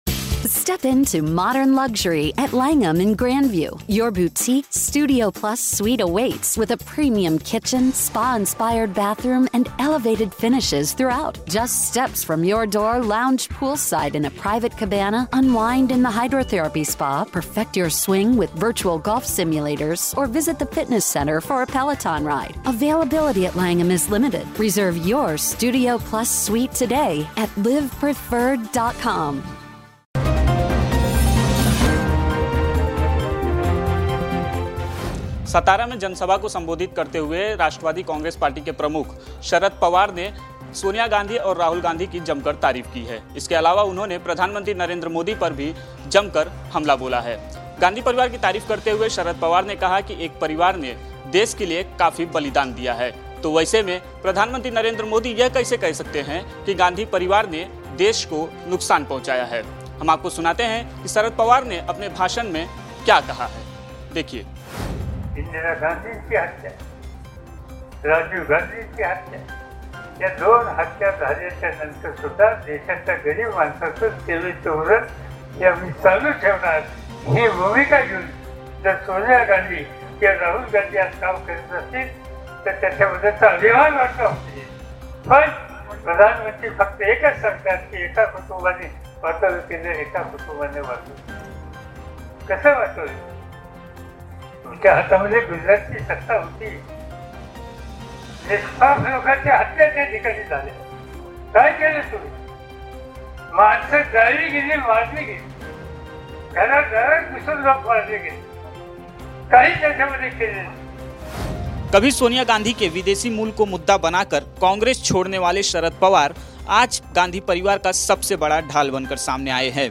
न्यूज़ रिपोर्ट - News Report Hindi / सोनिया और राहुल गांधी का शरद पवार ने की तारीफ़, पीएम मोदी पर साधा निशाना